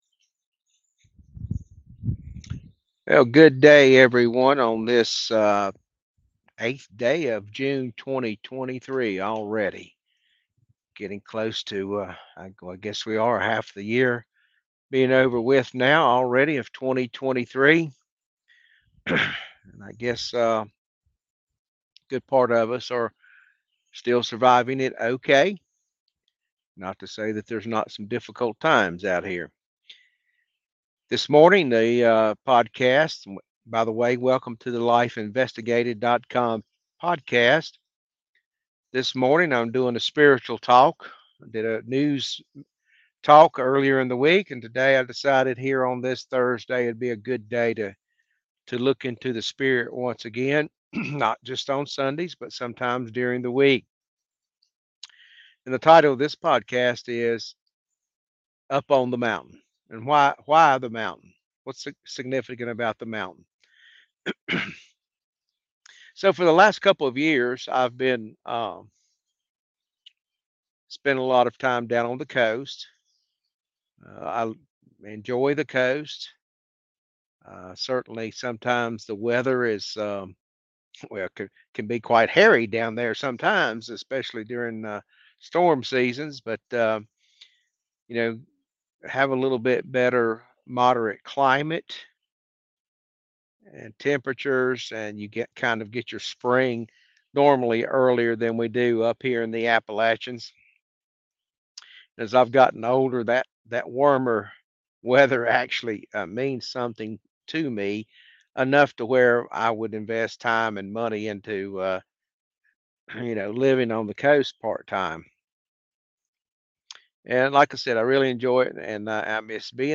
Spiritual Talk # 4 Need to be uplifted? Try going to the Mountain.